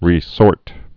(rē-sôrt)